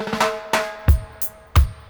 90-FILL-FX.wav